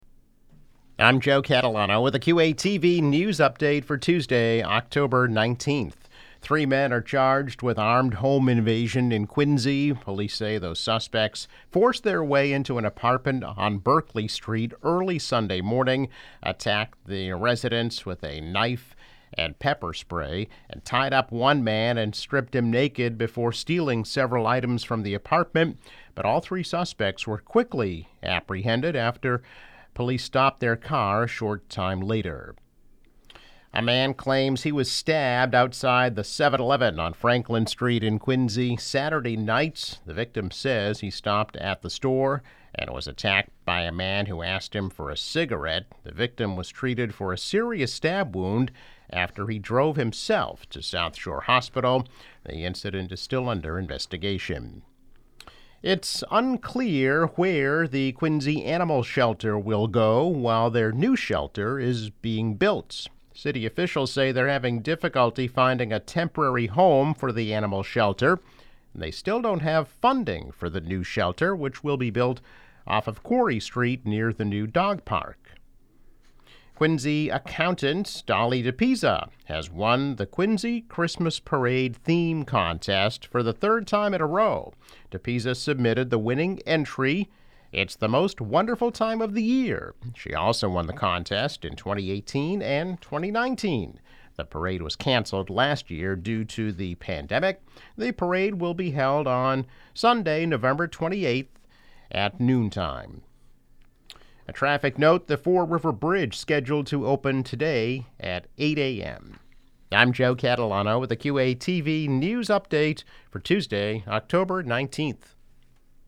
News Update - October 19, 2021